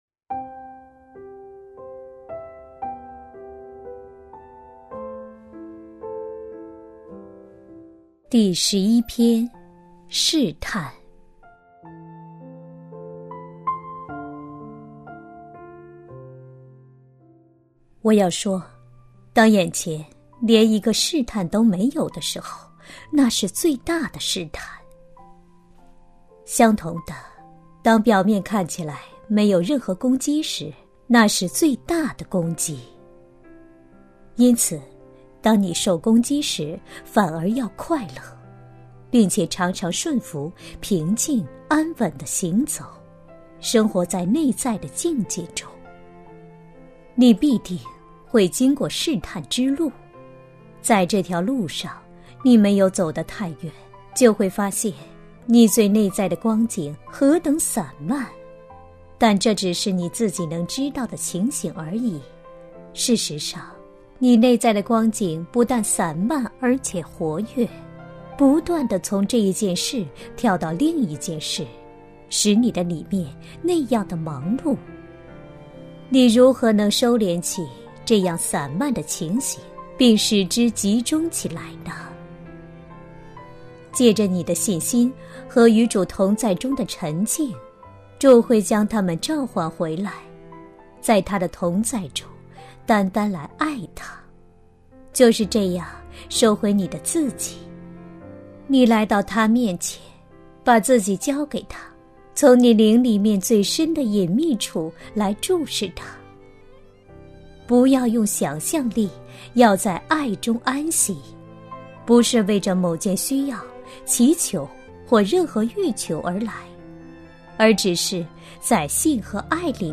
首页 > 有声书 | 灵性生活 | 灵程指引 > 灵程指引 第十一篇：试探